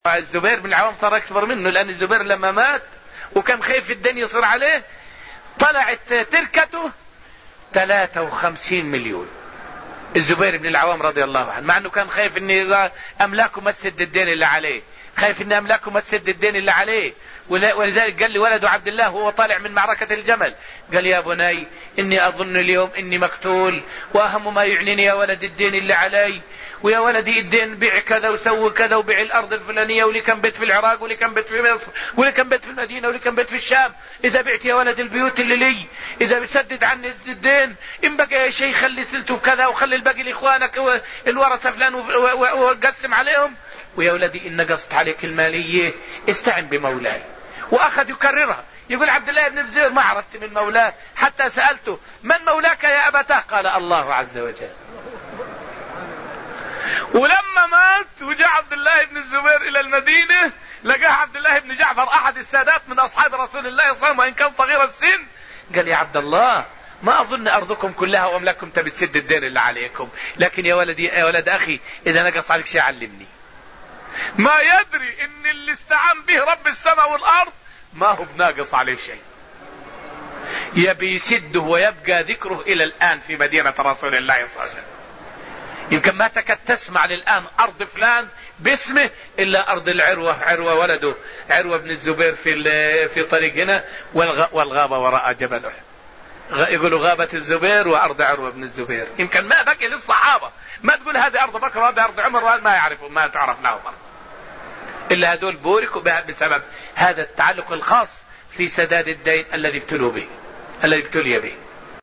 فائدة من الدرس الحادي عشر من دروس تفسير سورة النجم والتي ألقيت في المسجد النبوي الشريف حول سرعة استجابة الصحابة رضوان الله تعالى عنهم لأمر الله تعالى وأمر رسوله صلى الله عليه وسلم.